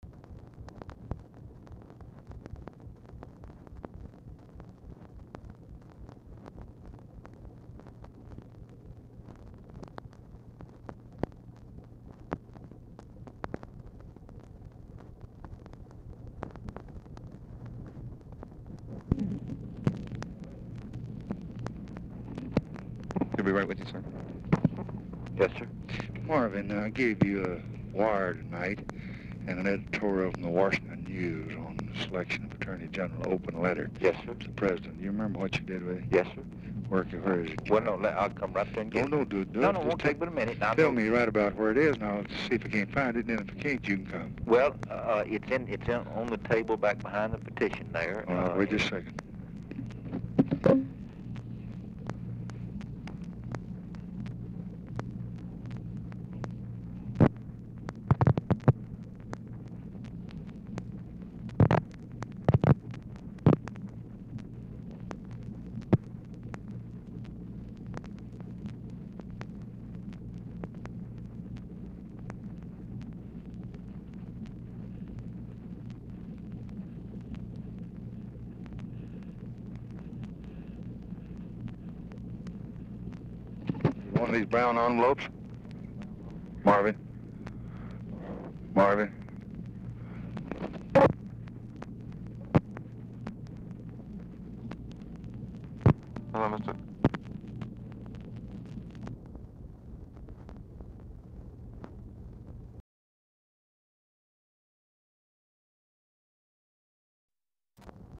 Telephone conversation
Format Dictation belt
Location Of Speaker 1 LBJ Ranch, near Stonewall, Texas